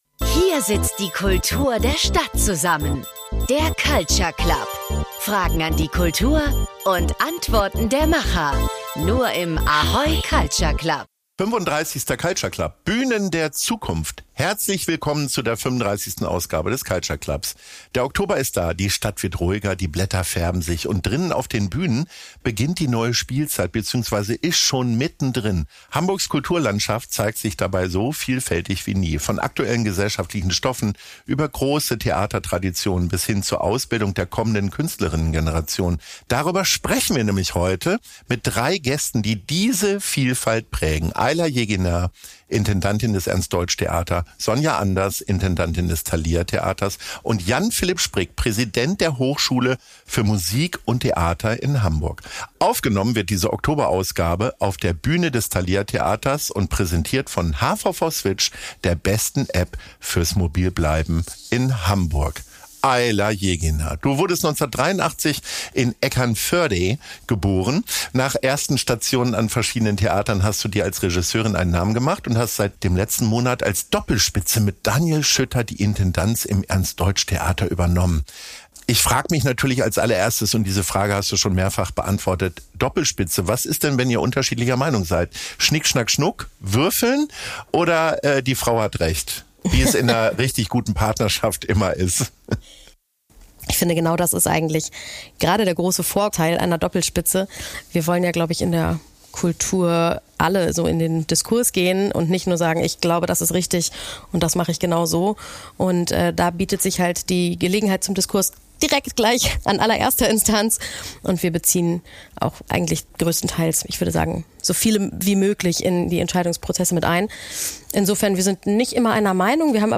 Bühnen der Zukunft ~ Culture Club - Der Kulturtalk bei ahoy, präsentiert von hvv switch Podcast